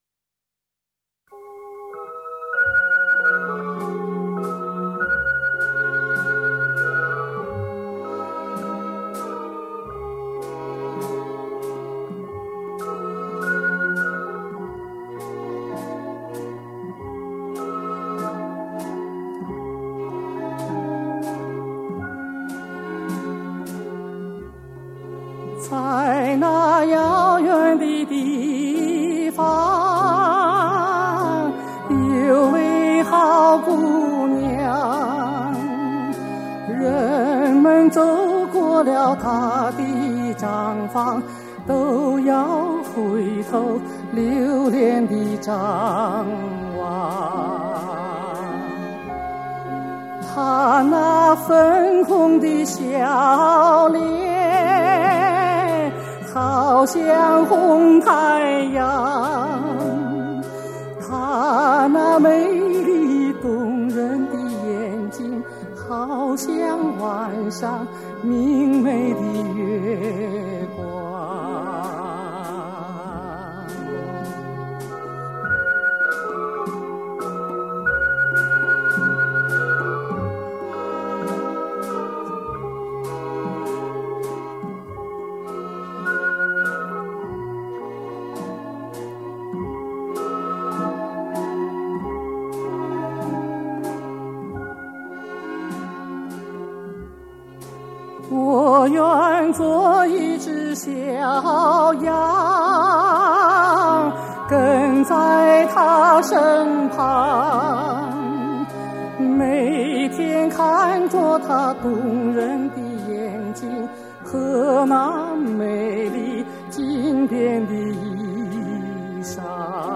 尤其是他的高音C，明亮、清澈、浑厚，到今天仍然没有人能超越。
他音域宽广、音色纯美，演唱富于乐感，以情带声、以情并茂吐字清晰、行腔流畅，情趣表达准确、艺术形象鲜明。